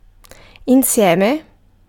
Ääntäminen
US : IPA : /wɪð/